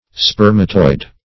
Search Result for " spermatoid" : The Collaborative International Dictionary of English v.0.48: Spermatoid \Sper"ma*toid\, a. [Spermato- + -oid.]